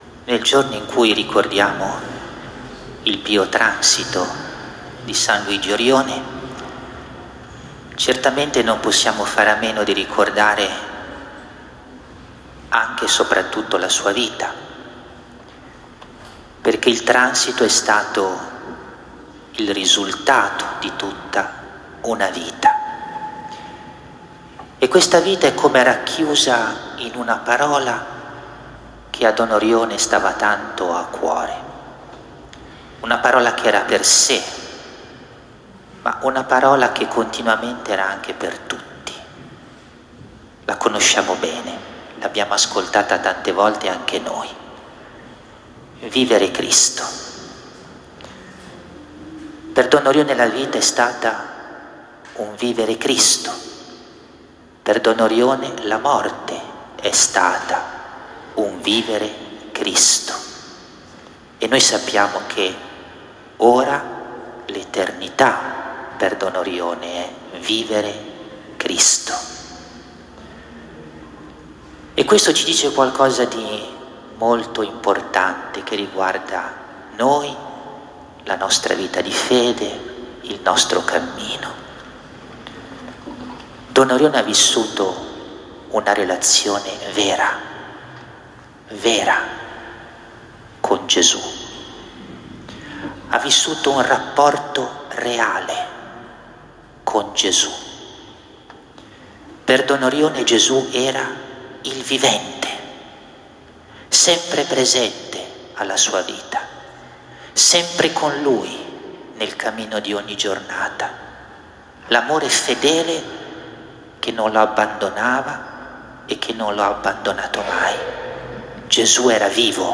Domenica 16 marzo 2025, in Santuario è stato celebrato il ricordo del Pio Transito di Don Orione. La Celebrazione Eucaristica è stata presieduta dal nostro Vescovo diocesano Mons. Guido Marini